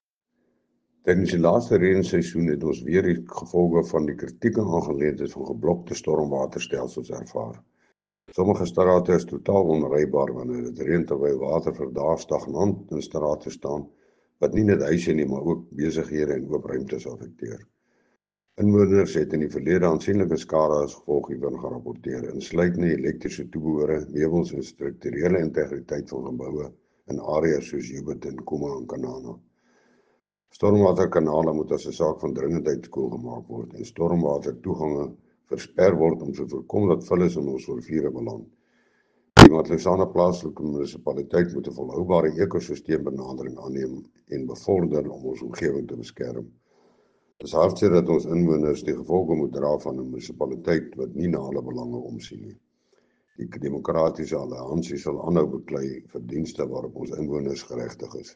Note to broadcasters: Please find linked soundbites in
Afrikaans by cllr Gerhard Strydom